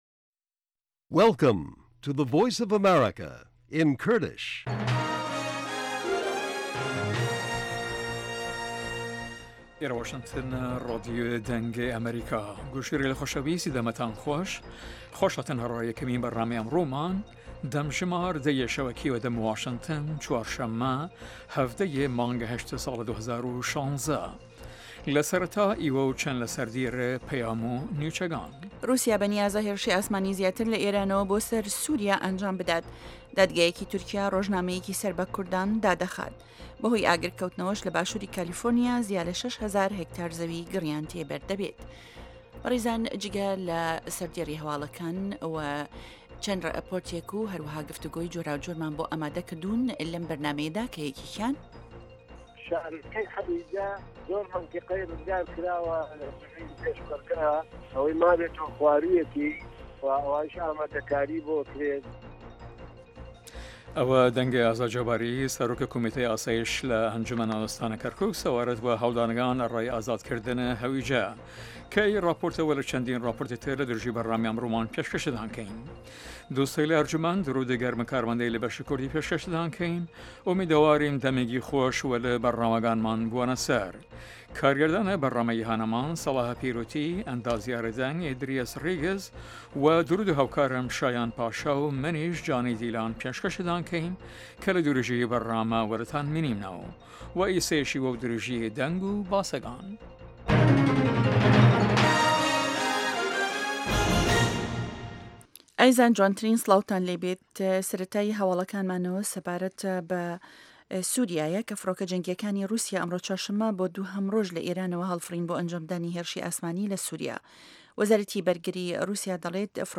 هه‌واڵه‌کان، ڕاپـۆرت، وتووێژ،